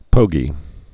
(pōgē)